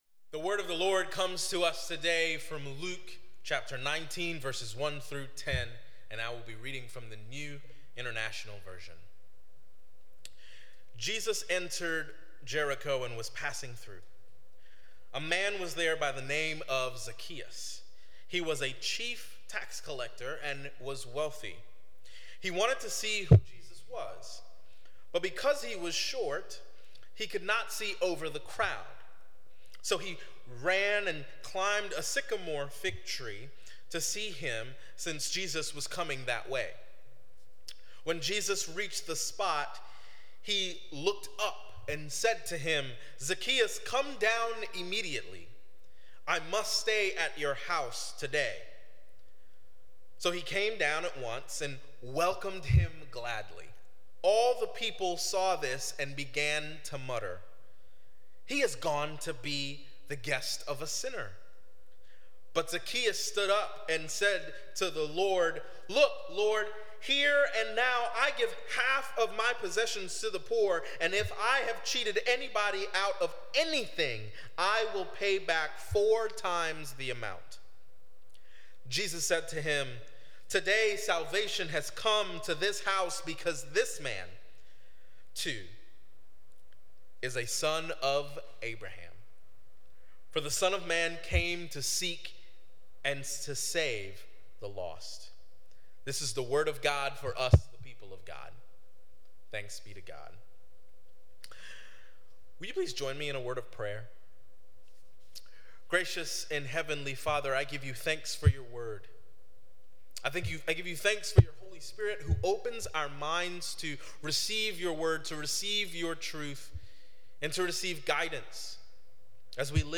On Sunday, Sept. 10, St. Stephen kicked off all our fall programming for children, youth, and adults, including small groups and other fellowship opportunities.